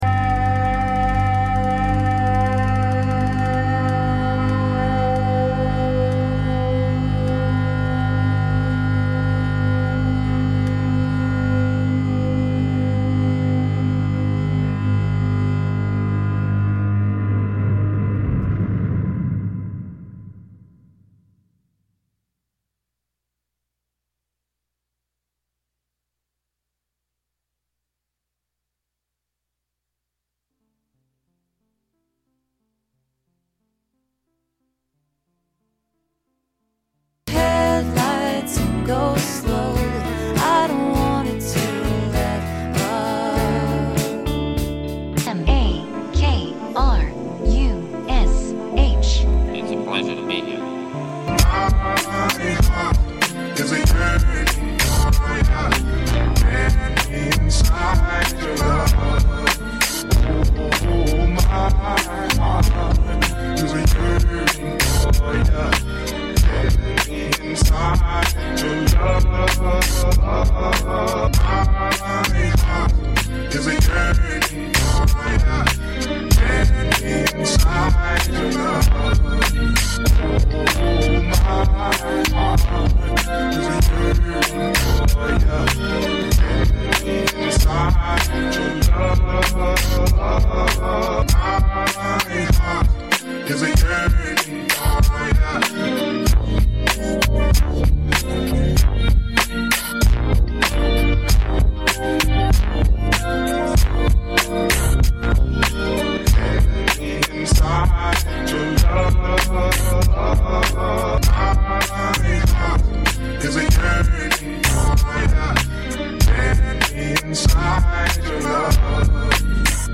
For Fans Of: Suburban Lawns, Cows, Joy Division, Ethereal Tomb, Rage Against The Machine, Captain Beefheart, and always being more tired than you are awake.